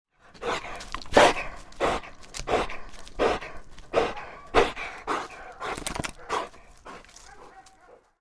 Звуки собаки, отряхивания
На этой странице собраны звуки собак, которые отряхиваются после воды или просто встряхивают шерсть.
Звук собаки, выдыхающей после прогулки и встряхивающей шерсть